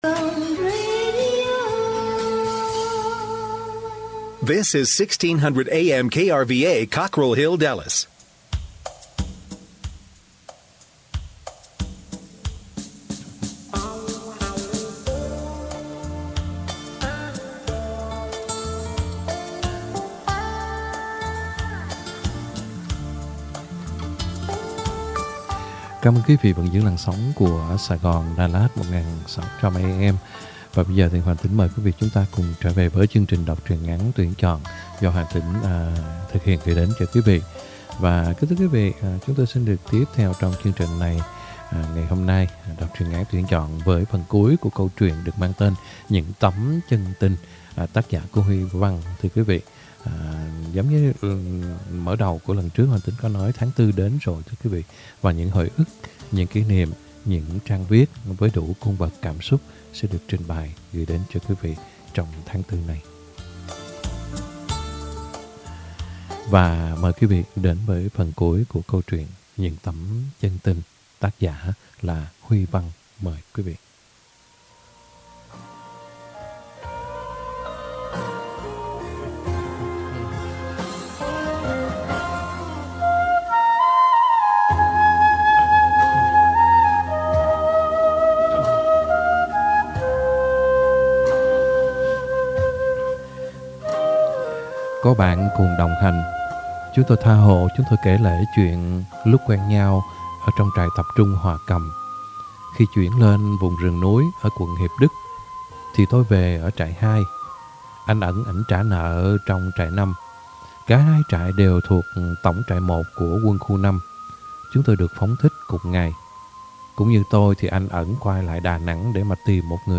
Đọc Truyện Ngắn = Những Tấm Chân Tình (2 end) - 04/12/2022 .